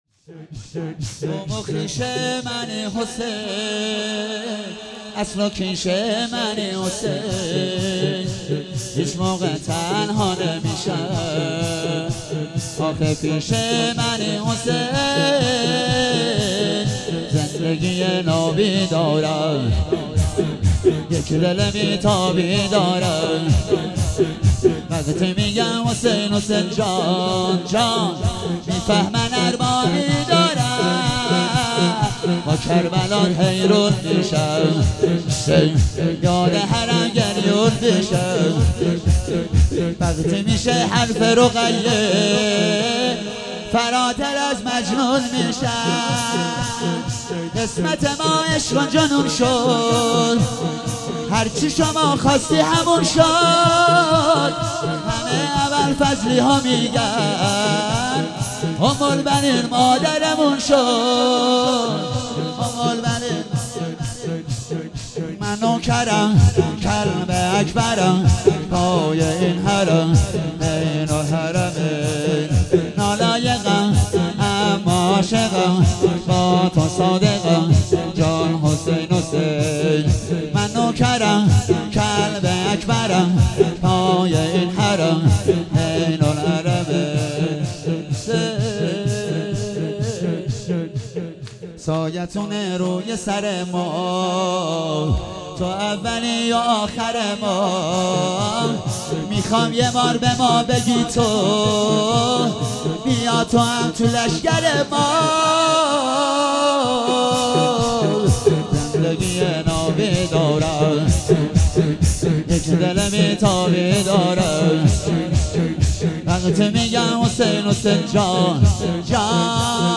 هفتگی 16 فروردین 97 - شور - قوم و خیش منی حسین